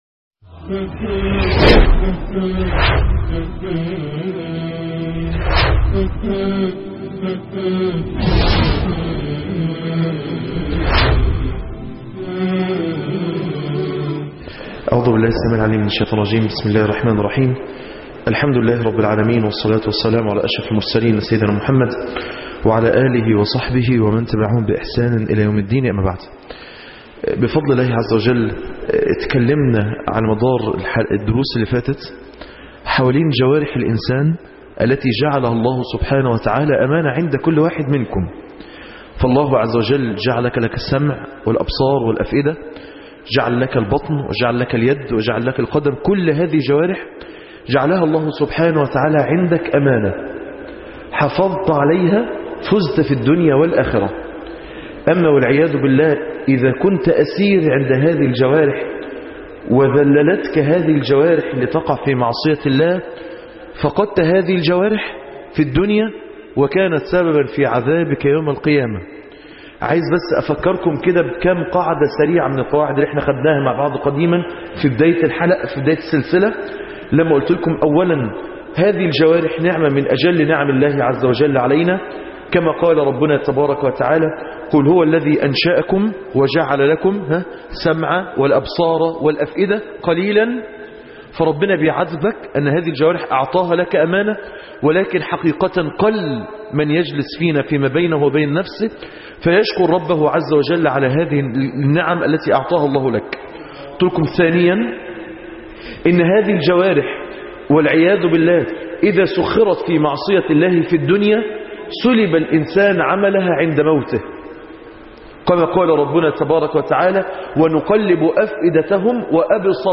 الدرس الثامن_البصر_1